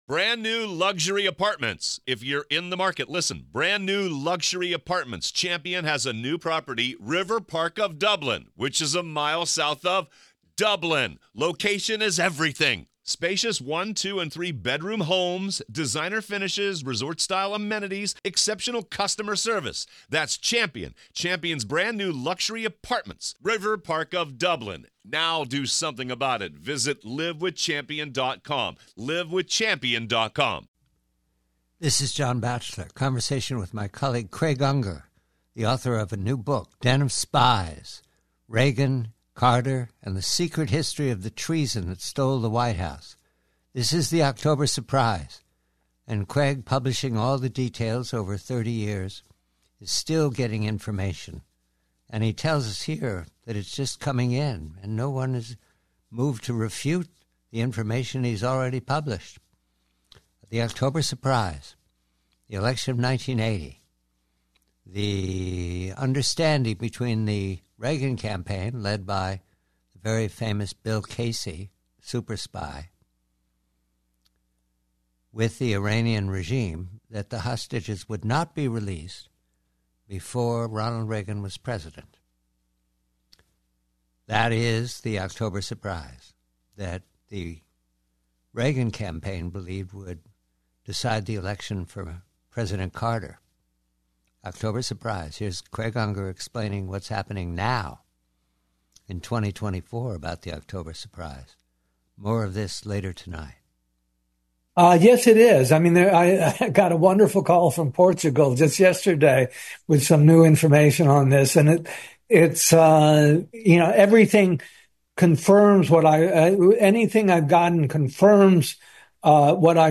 Headliner Embed Embed code See more options Share Facebook X Subscribe PREVIEW: REAGAN: CARTER Tonight's extended conversation with Craig Unger will explore his new book "Den of Spies" investigating the controversial "October Surprise" allegations. The discussion centers on claims that Reagan campaign manager Bill Casey orchestrated a secret deal with Iran regarding the timing of the release of 52 American hostages during the 1980 presidential campaign between incumbent Jimmy Carter and challenger Ronald Reagan.